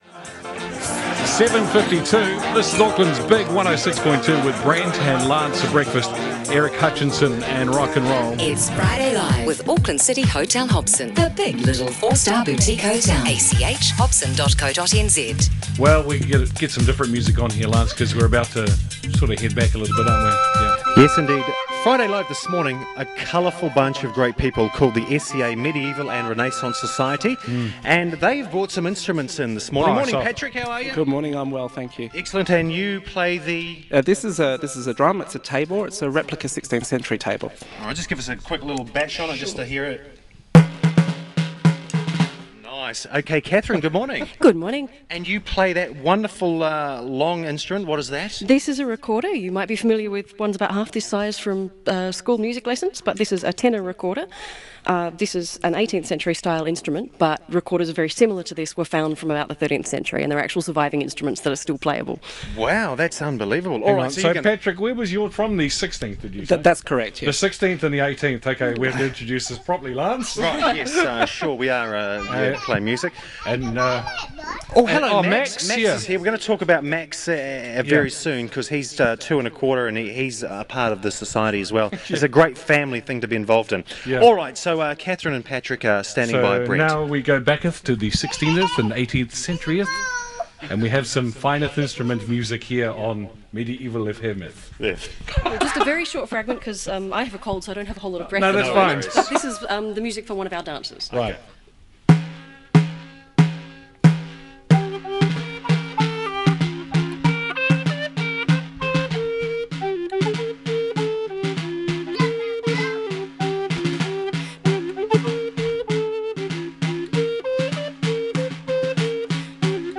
More correctly, the hosts of BigFM invited us to come along and be part of their Friday breakfast show and to talk about who we are and what we do.